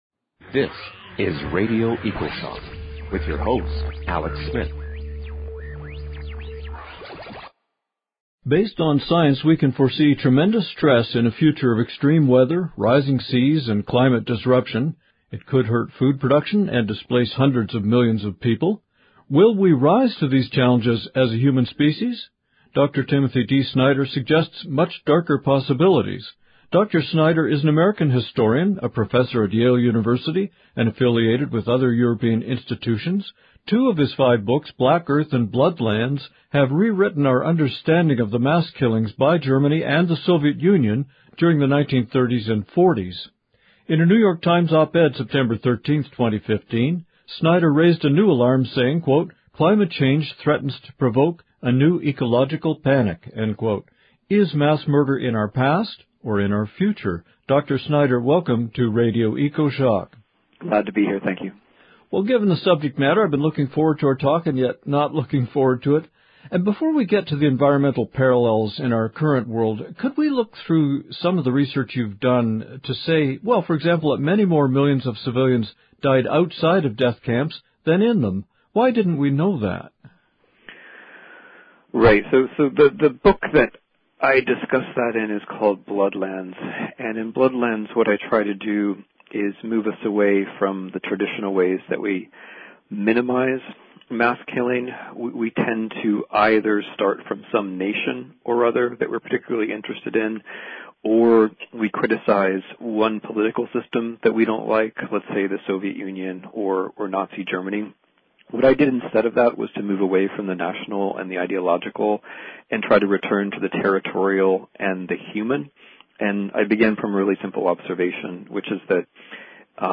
Dr. Tim Flannery We're about to hear Tim Flannery speak about his new book, at the Town Hall in Seattle, on November 12, 2015.